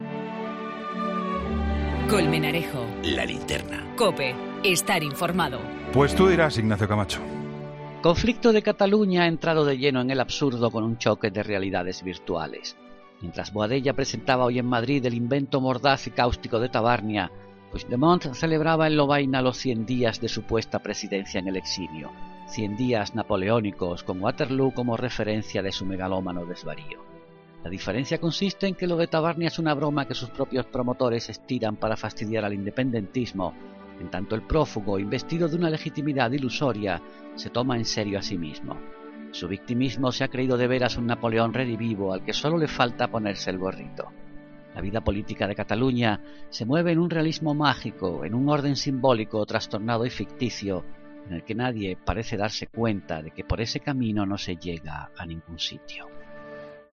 Ignacio Camacho comenta en 'La Linterna' el acto celebrado en Lovaina por los 100 días de Puigdemont como supuesto presidente en el exilio.